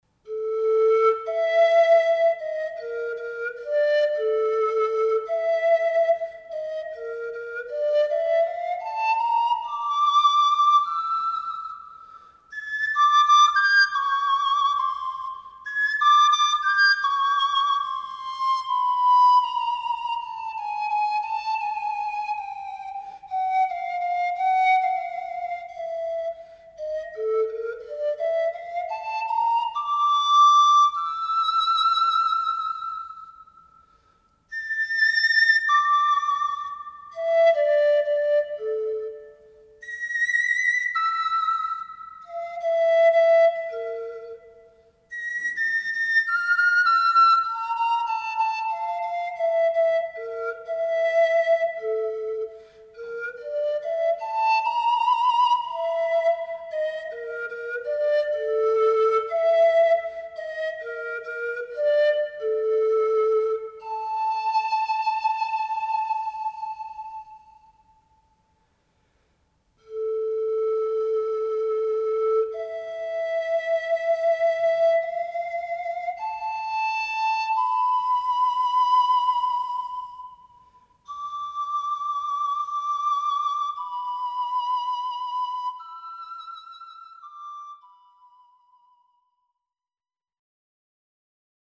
Drei Miniaturen für Panflöte solo